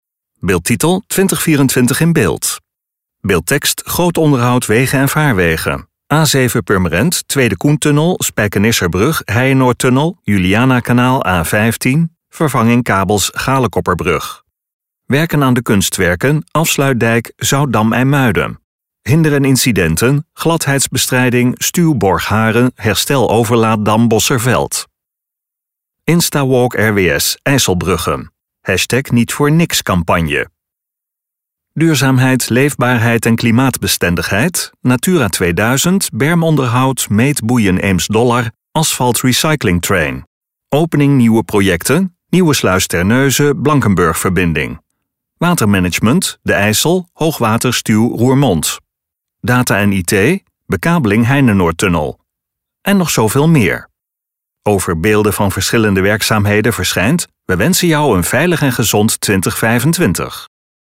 BEKLIJVENDE MUZIEK TOT HET EIND VAN DE VIDEO (Werken aan de kunstwerken: Afsluitdijk, Zoutdam IJmuiden.